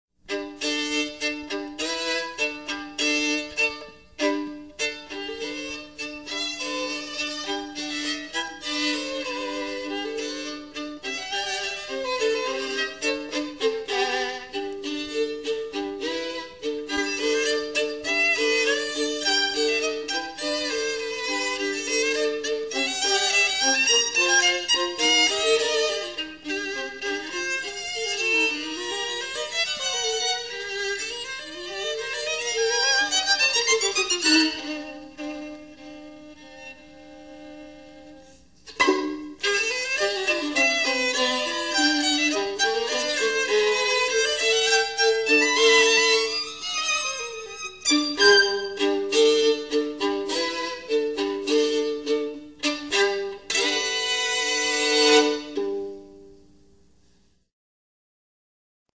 first a medium tempo folk-like dance